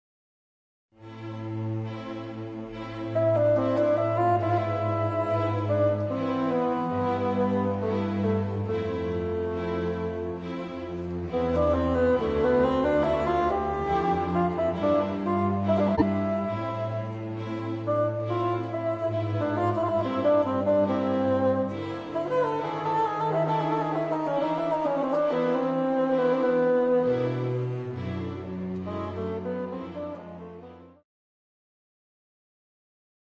moderne Passacaglia